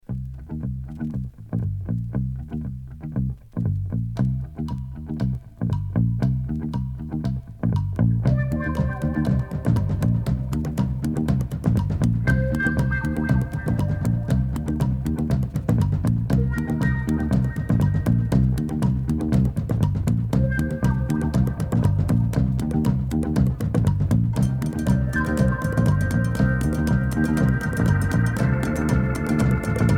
Rock et groove